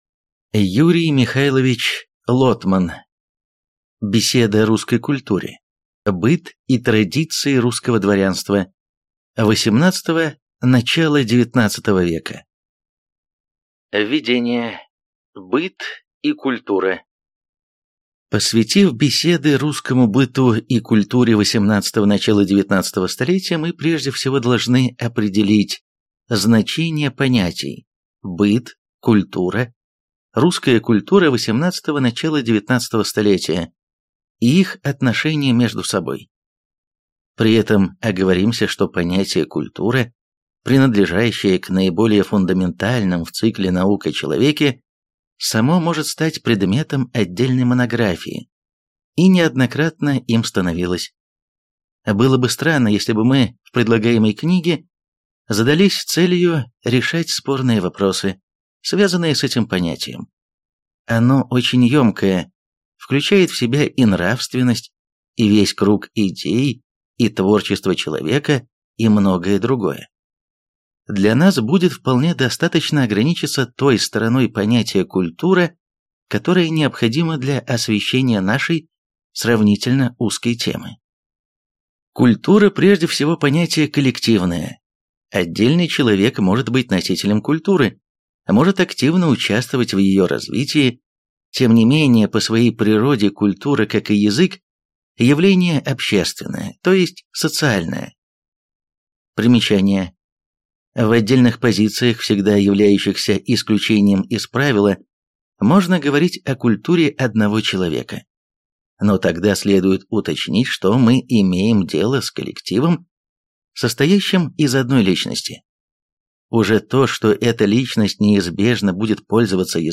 Аудиокнига Беседы о русской культуре: Быт и традиции русского дворянства (XVIII – начало XIX века) | Библиотека аудиокниг